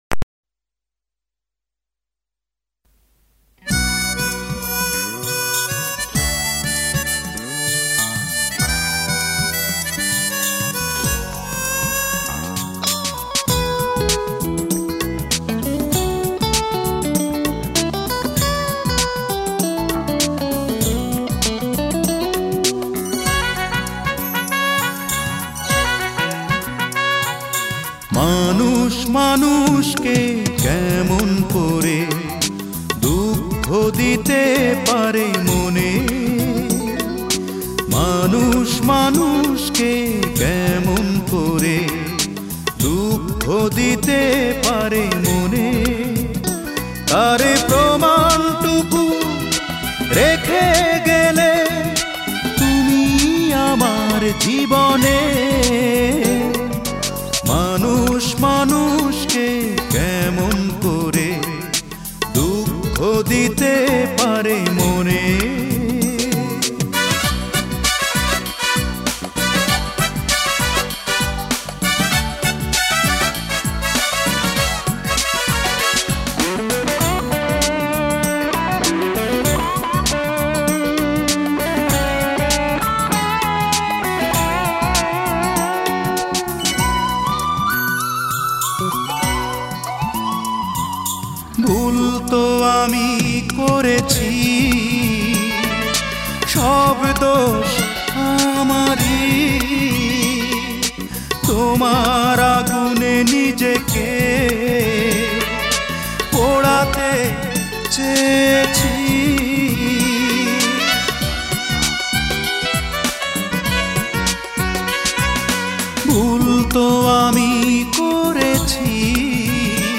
Genre Adhunik Bangla